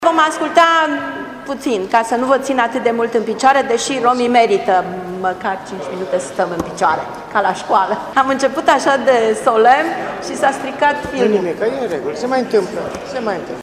Manifestările dedicate romilor de pretutindeni au fost organizate miercuri,  în sala de Consiliu Local a Primăriei Timișoara și ar fi trebuit să înceapă cu intonarea imnului internațional al rromilor. Momentul festiv a fost întrerupt însă, pentru că, pe videoproiectorul primăriei, nu era afișat și videoclipul imnului.